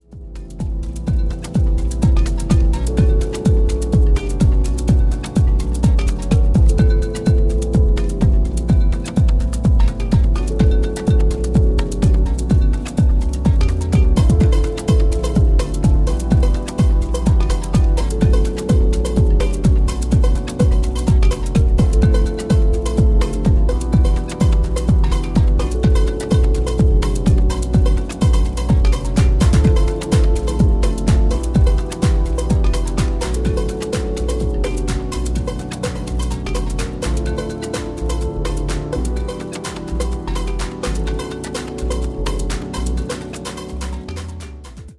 Two psychedelic jacking techno/house jams
House Techno